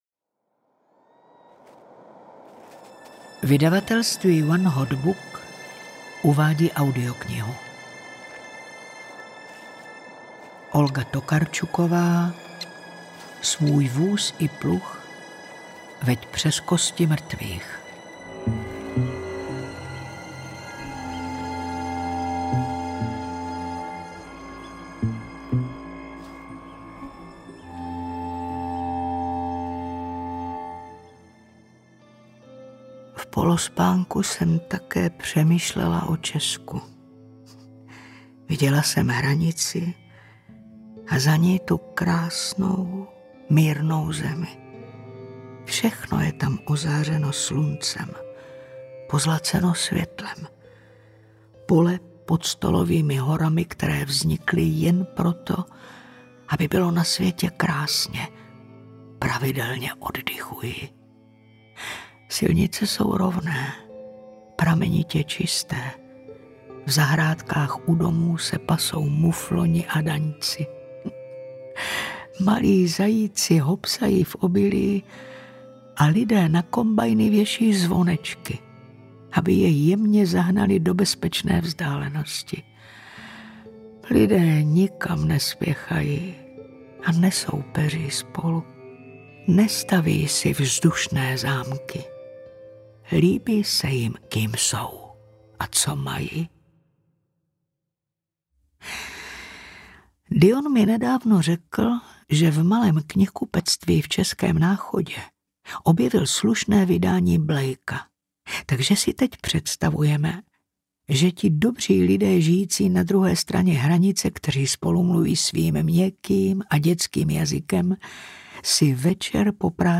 Audiokniha Svůj vůz i pluh veď přes kosti mrtvých, kterou napsala Olga Tokarczuk.
Ukázka z knihy